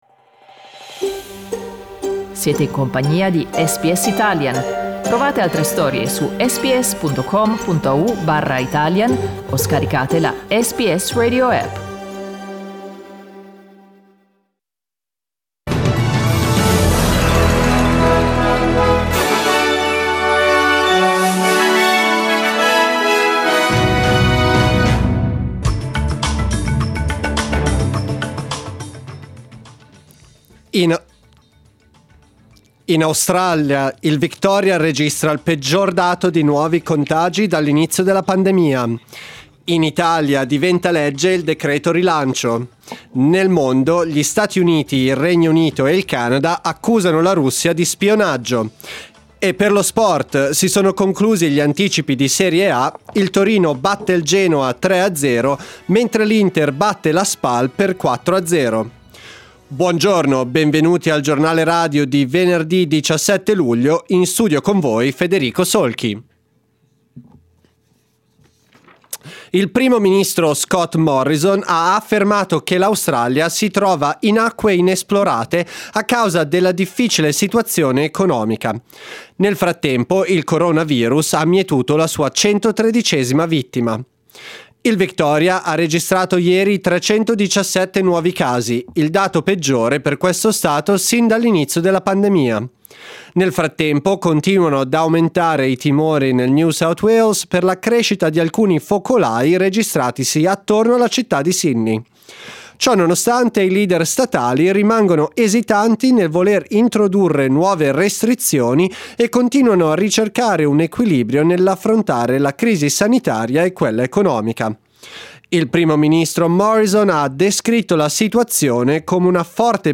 News in Italian 17 July 2020